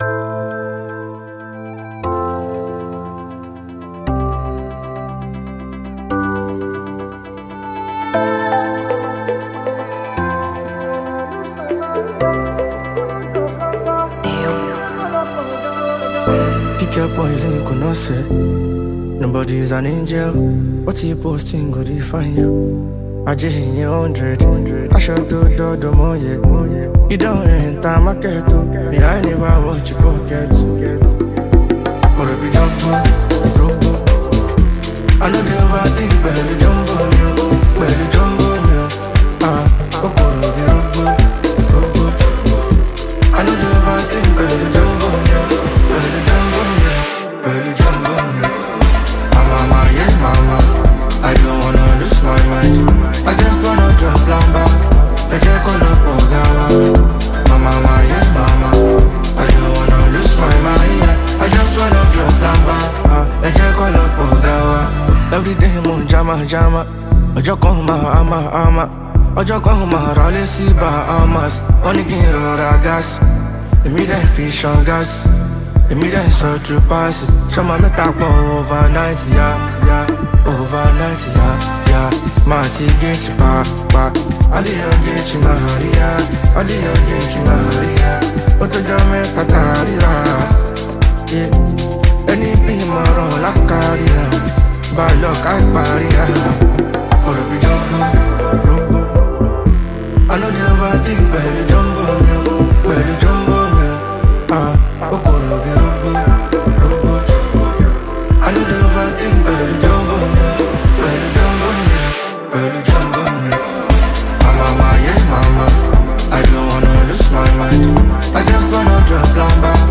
Nigerian classic singer